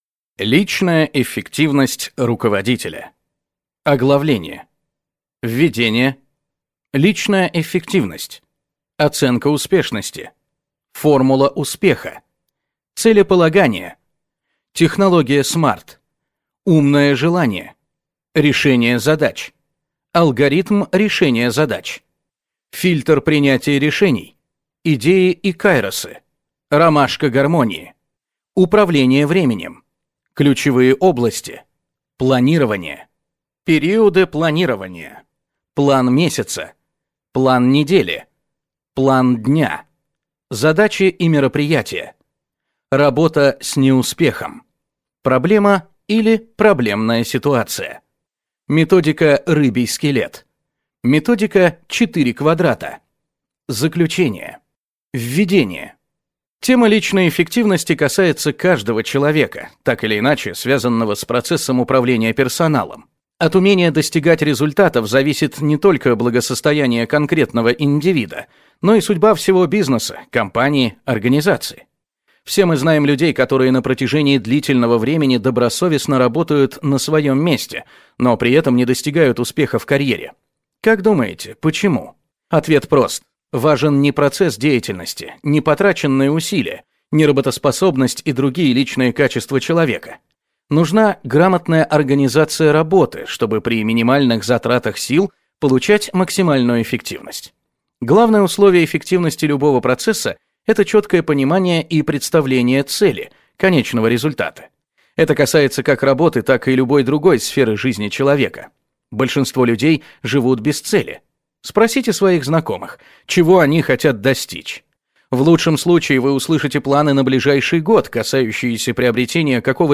Аудиокнига Личная эффективность руководителя | Библиотека аудиокниг